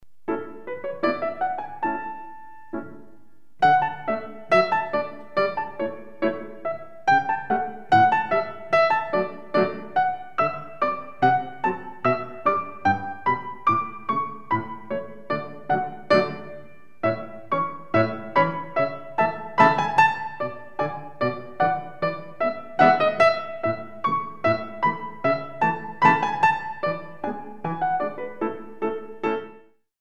Piano Arrangements of Classical Compositions